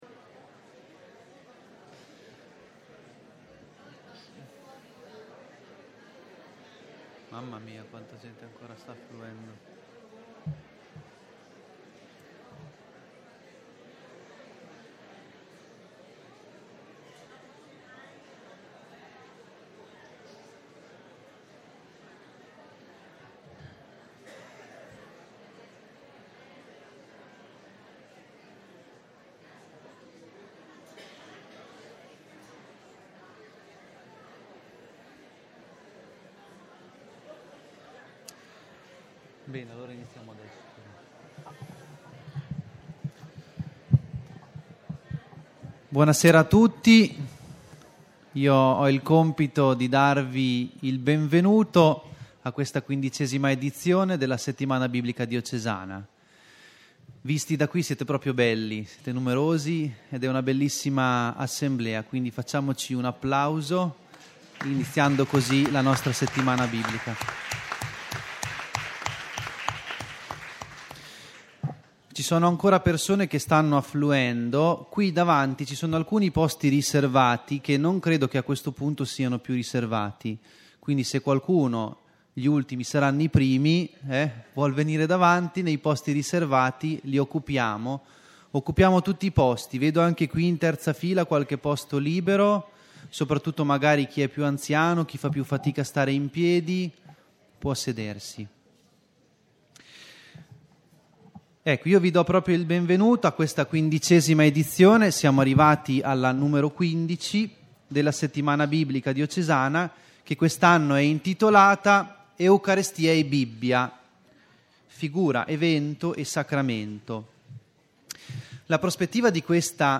Attenzione- Le registrazioni audio partono qualche minuto prima dell’inizio, attendere pazientemente l’inizio della relazione.